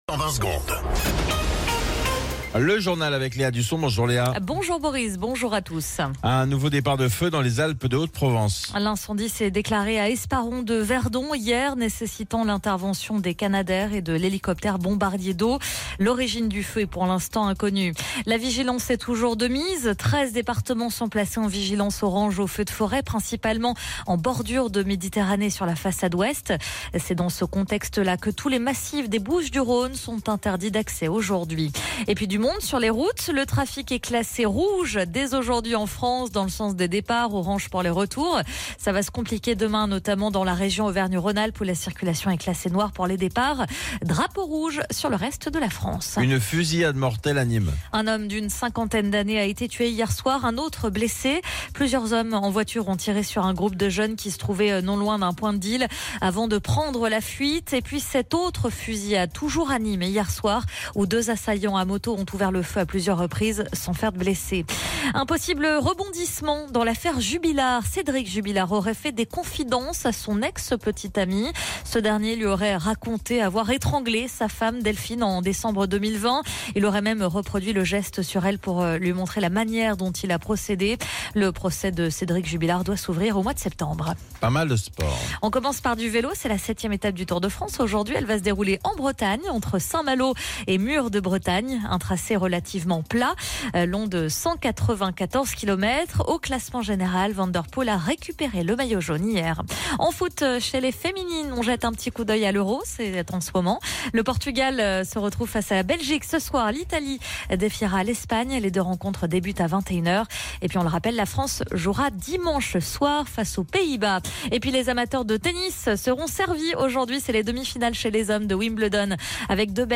Flash Info National 11 Juillet 2025 Du 11/07/2025 à 07h10 .